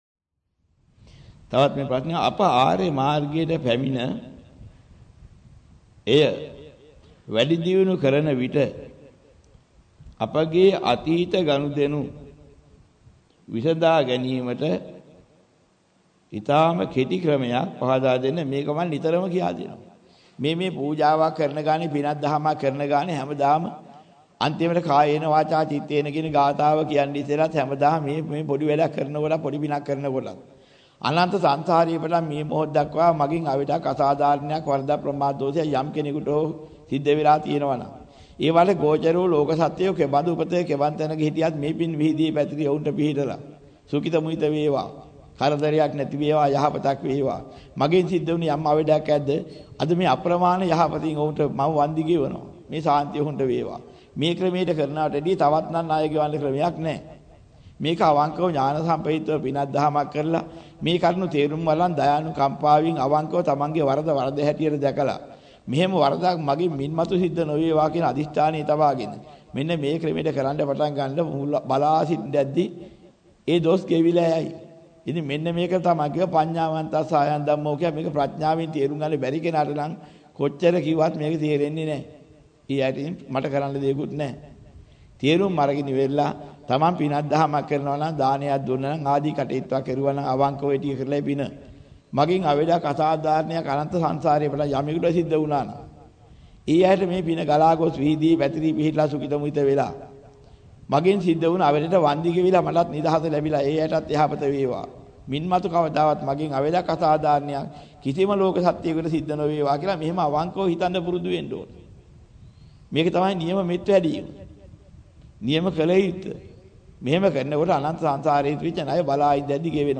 වෙනත් බ්‍රව්සරයක් භාවිතා කරන්නැයි යෝජනා කර සිටිමු 11:59 10 fast_rewind 10 fast_forward share බෙදාගන්න මෙම දේශනය පසුව සවන් දීමට අවැසි නම් මෙතැනින් බාගත කරන්න  (5 MB)